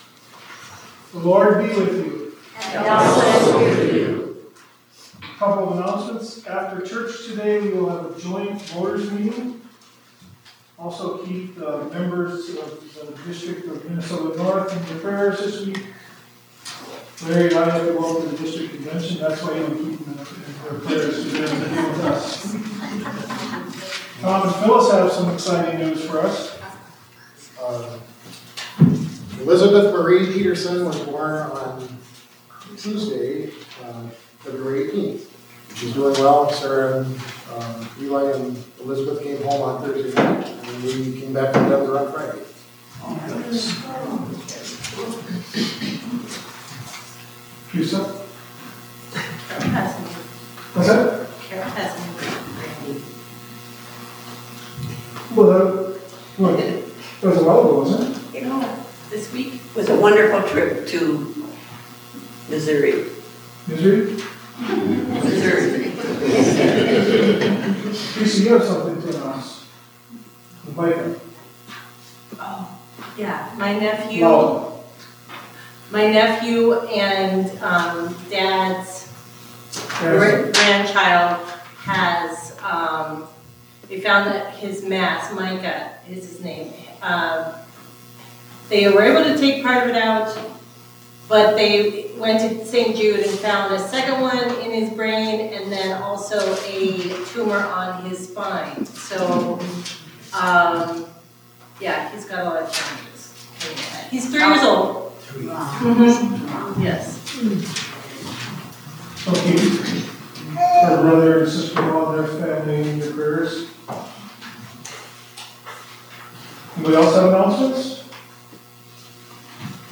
Zion Worship 23 Feb 25
Zion-Worship-23-Feb-25.mp3